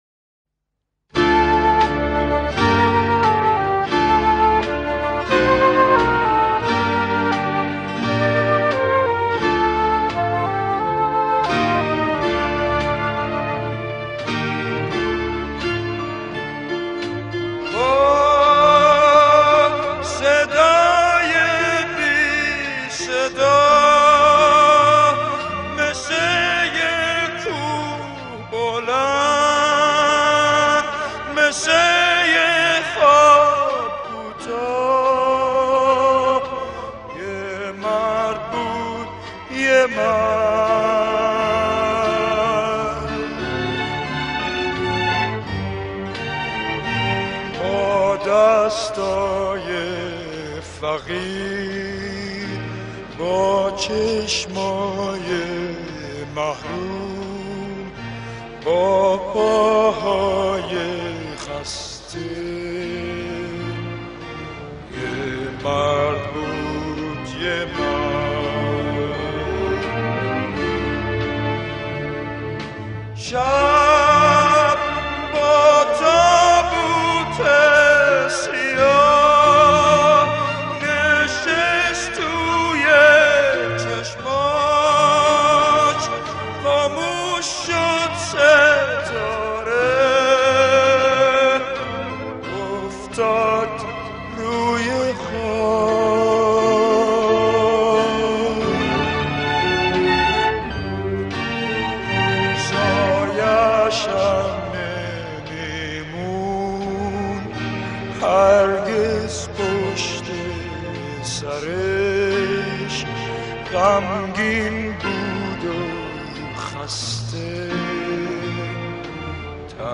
اهنگ غمگین